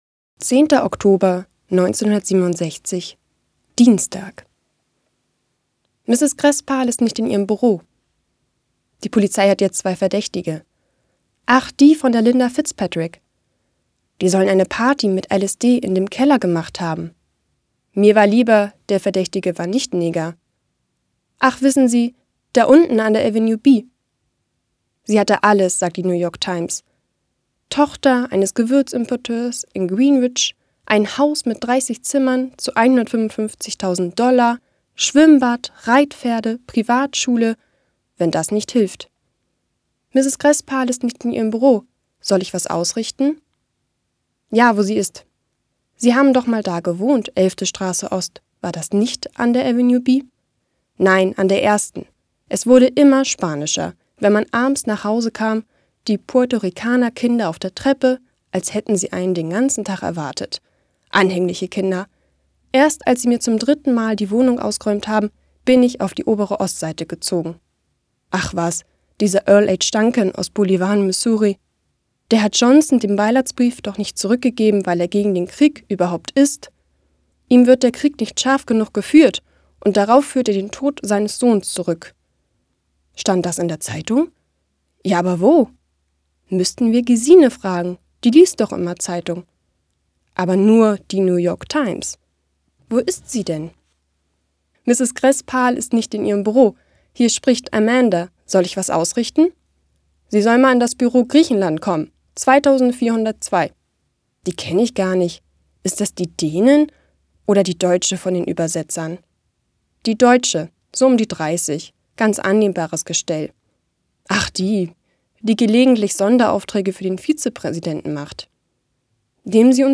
Eine Stadt liest Uwe Johnsons Jahrestage - 10.